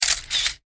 Photo_shutter.ogg